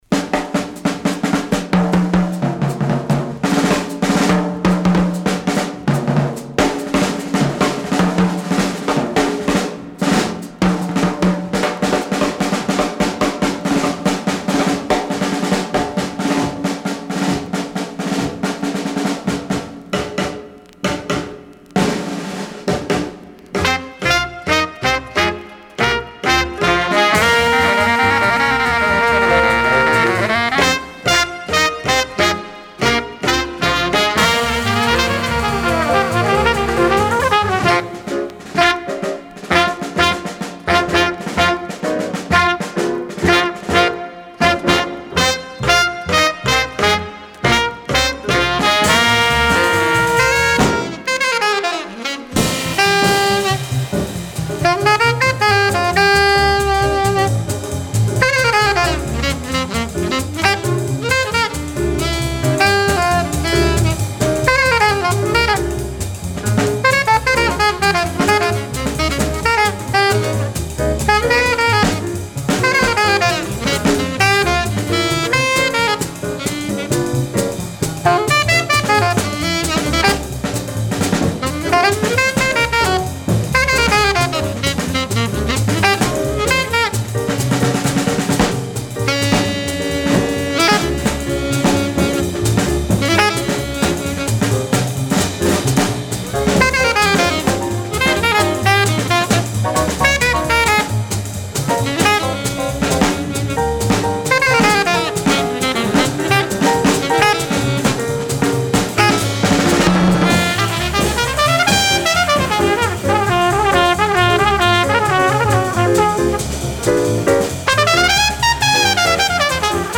mono pressing